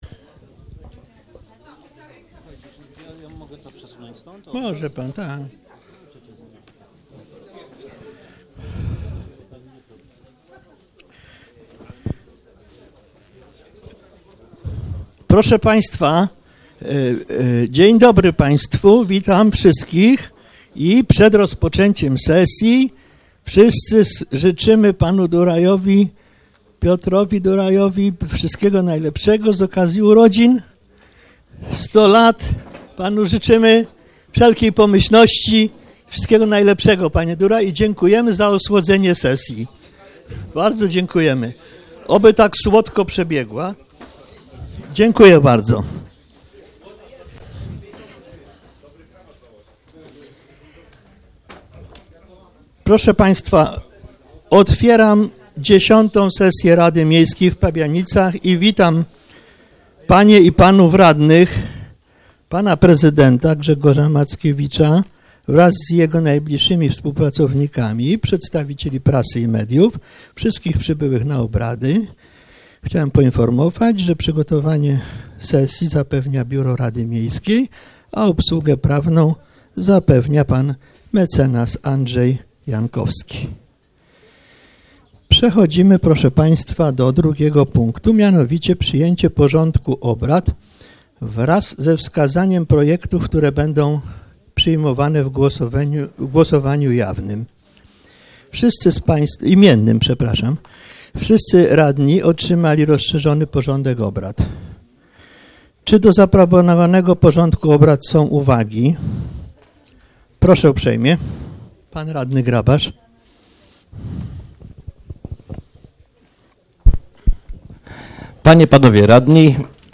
X sesja Rady Miejskiej w Pabianicach - 23 kwietnia 2015 r. - 2015 rok - Biuletyn Informacji Publicznej Urzędu Miejskiego w Pabianicach